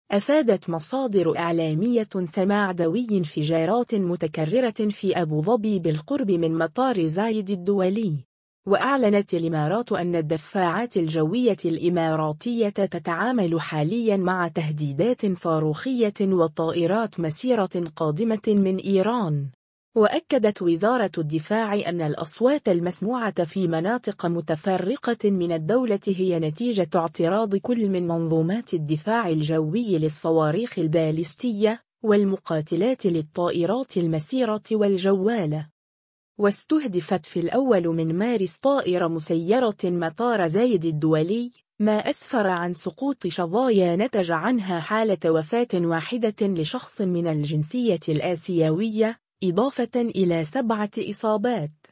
سماع دوي انفجارات متكررة في أبو ظبي بالقرب من مطار زايد الدولي